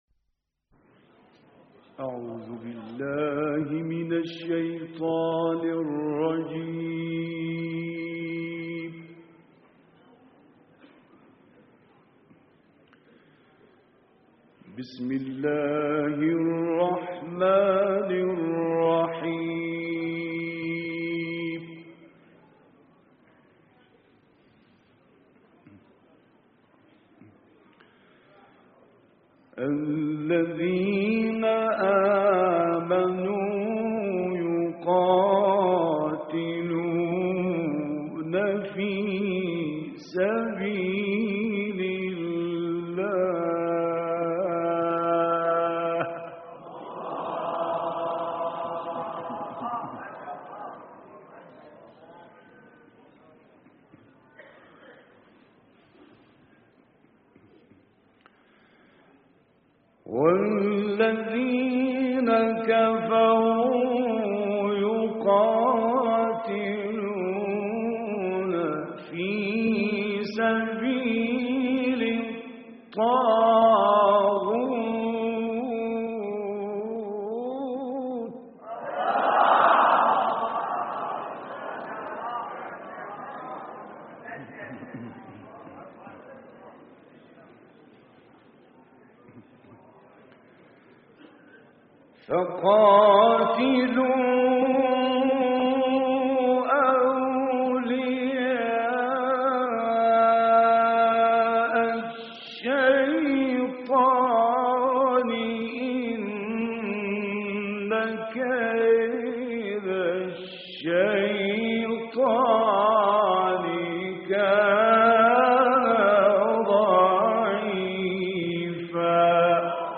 دانلود قرائت سوره نسا آیات 76 تا 81 - استاد راغب مصطفی غلوش